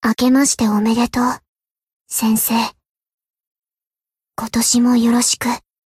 贡献 ） 分类:蔚蓝档案 ； 分类:蔚蓝档案语音 ；协议：Copyright 您不可以覆盖此文件。